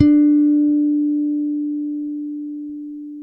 -MM JAZZ D 5.wav